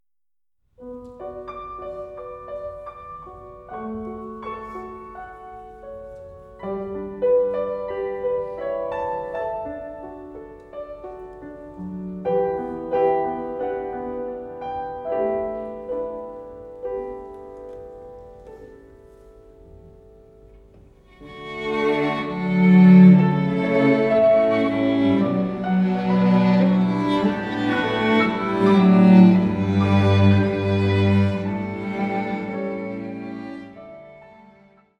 Streichensemble, Klavier
• kurzweilige Zusammenstellung verschiedener Live-Aufnahmen